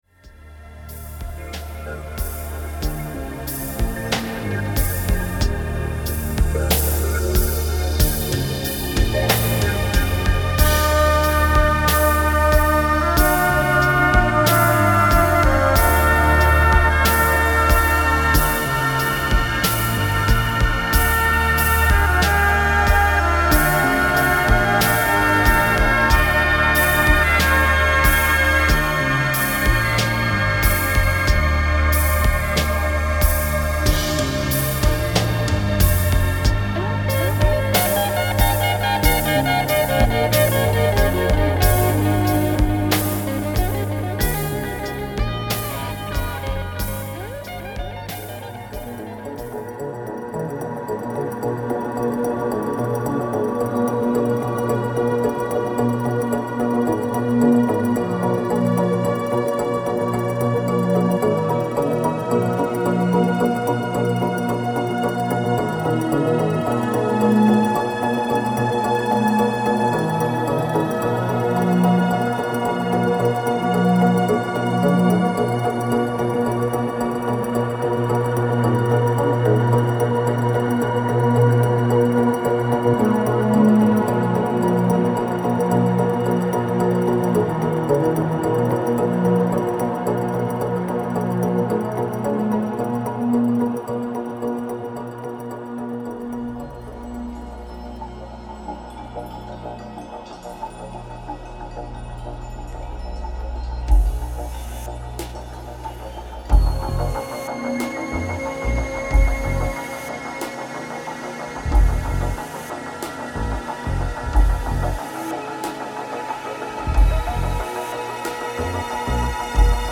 Ein sehr meditativer Track.